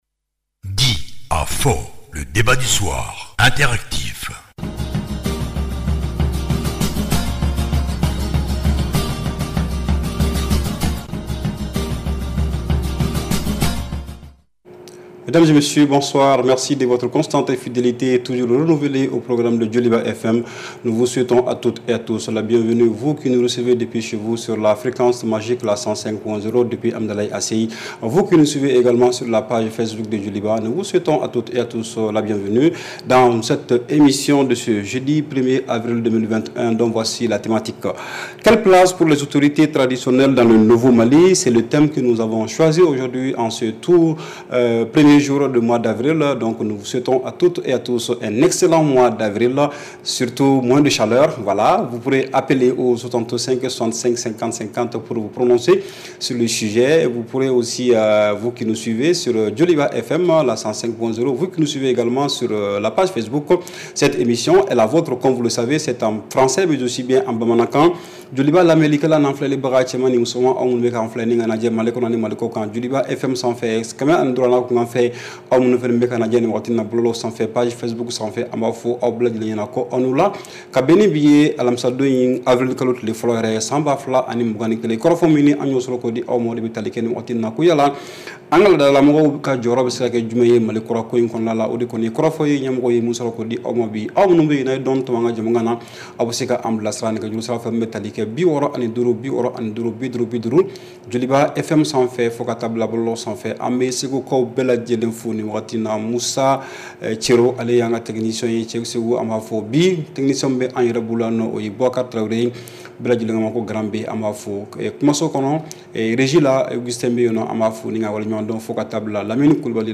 REPLAY 01/04 -« DIS ! » Le Débat Interactif du Soir